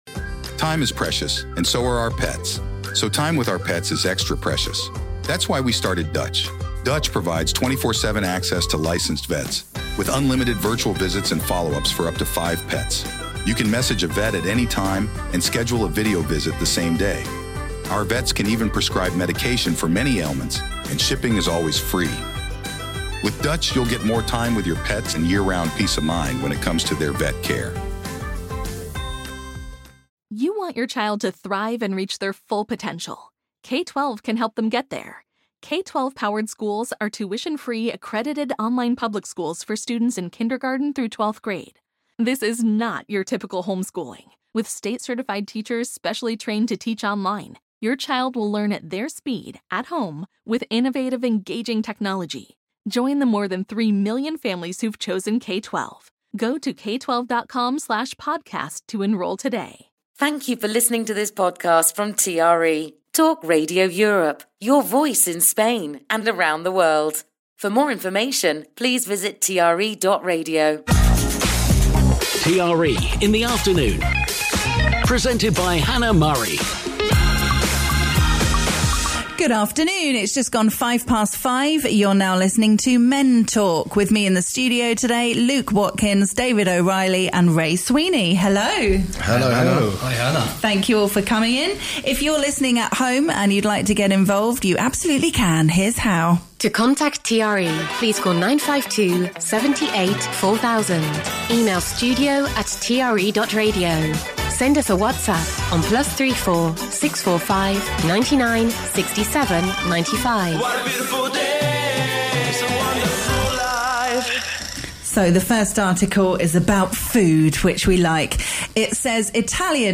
her panel of guests